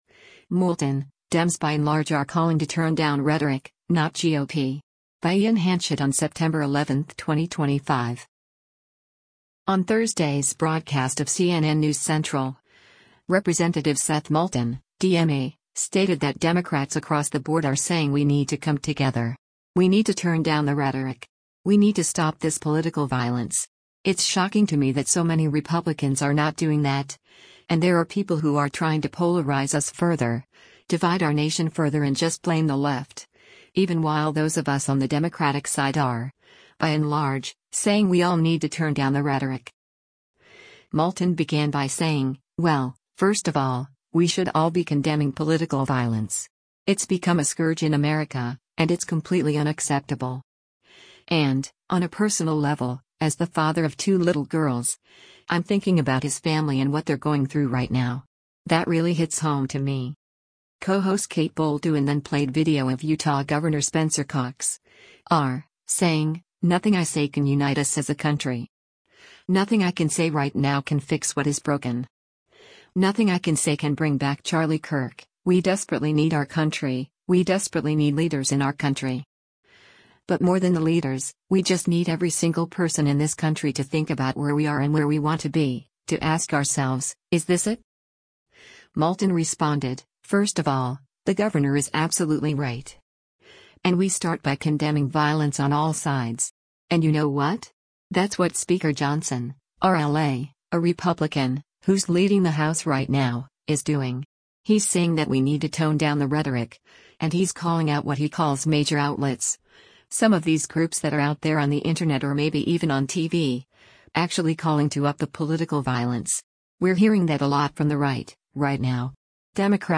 On Thursday’s broadcast of “CNN News Central,” Rep. Seth Moulton (D-MA) stated that “Democrats across the board are saying we need to come together. We need to turn down the rhetoric. We need to stop this political violence. It’s shocking to me that so many Republicans are not doing that,” and “There are people who are trying to polarize us further, divide our nation further and just blame the left, even while those of us on the Democratic side are, by and large, saying we all need to turn down the rhetoric.”